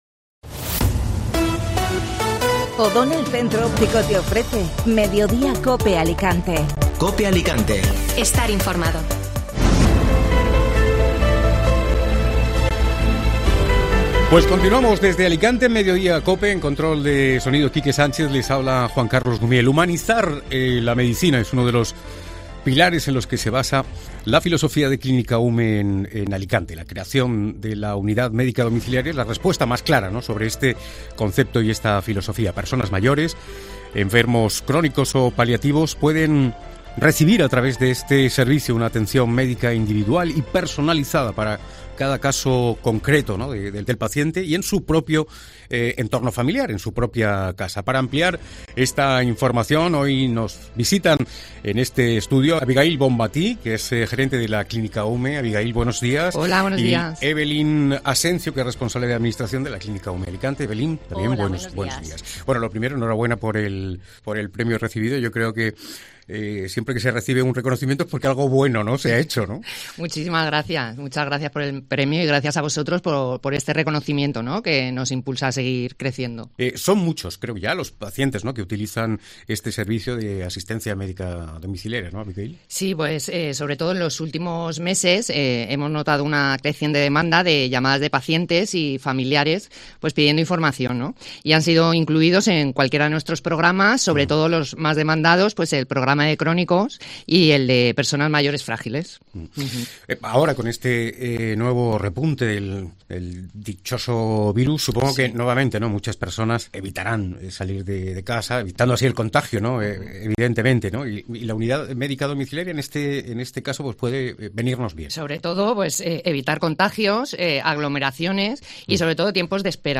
Hablamos con el concejal de Infraestructuras José Ramón González sobre la restauración de la fuente de Luceros y con expertos de la Clínica UME sobre la Unidad Médica Domiciliaria